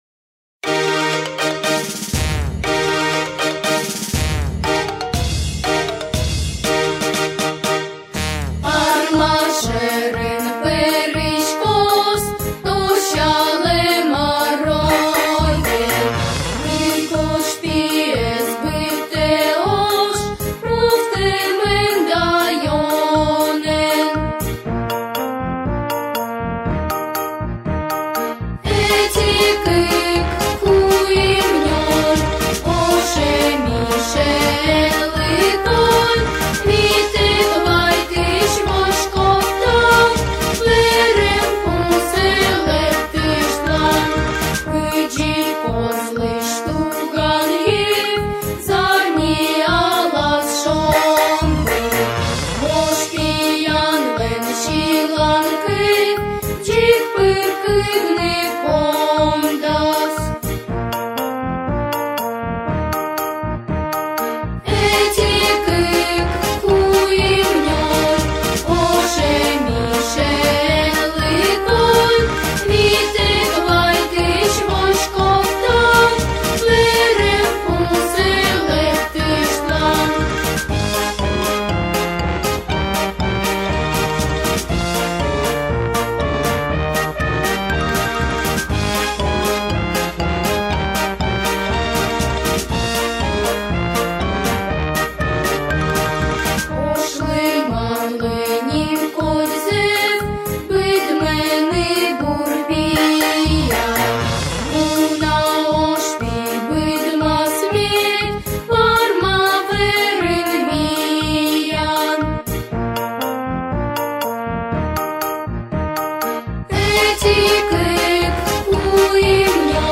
Сьыланкыв